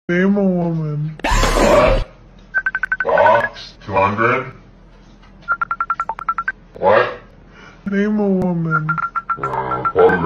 Goofy Ahh Ringtone Meme - Botão de Efeito Sonoro